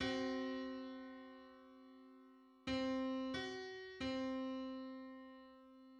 Just: 735/512 = 625.92 cents. Limit: 7-limit.
Public domain Public domain false false This media depicts a musical interval outside of a specific musical context.
Seven-hundred-thirty-fifth_harmonic_on_C.mid.mp3